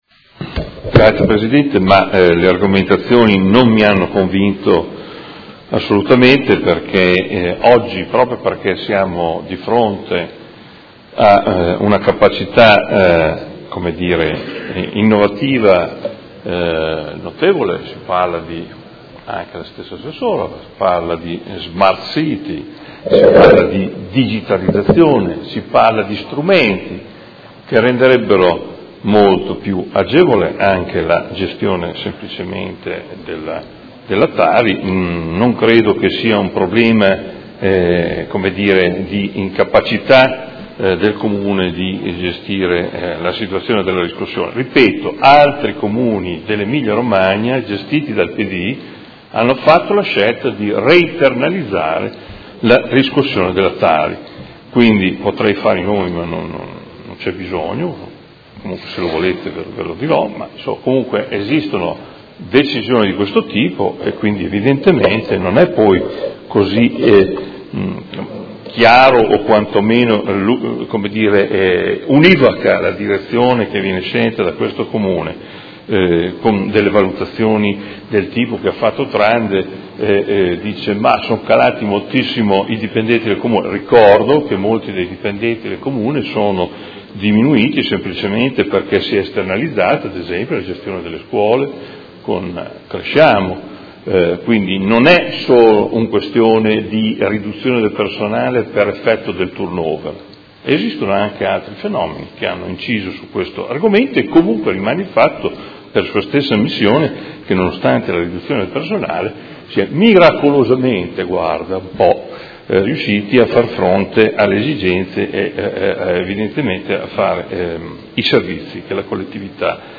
Adolfo Morandi — Sito Audio Consiglio Comunale
Seduta del 30/03/2017. Dichiarazione di voto su proposta di deliberazione: Convenzione per l’affidamento della gestione della riscossione del Tributo comunale sui rifiuti TARI anni 2017-2018 e per la regolamentazione della fatturazione e dei pagamenti del servizio di gestione dei rifiuti urbani ed assimilati (SGRUA) anno 2017